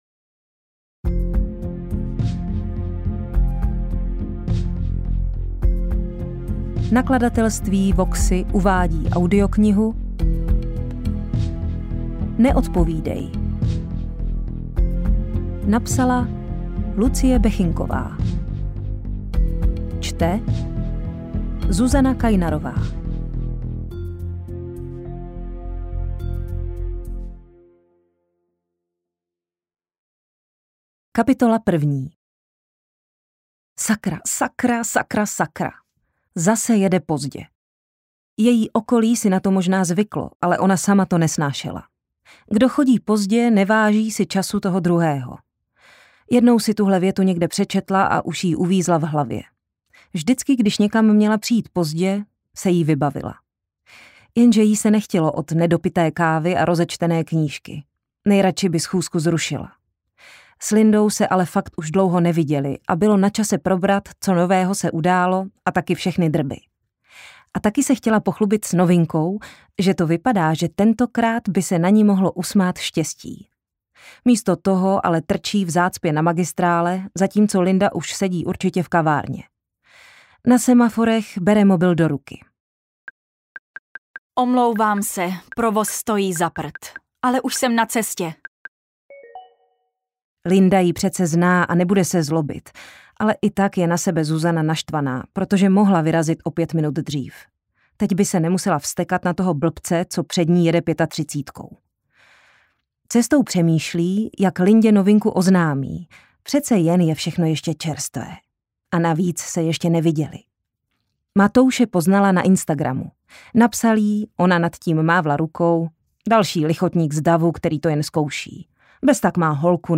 AudioKniha ke stažení, 31 x mp3, délka 8 hod. 29 min., velikost 463,2 MB, česky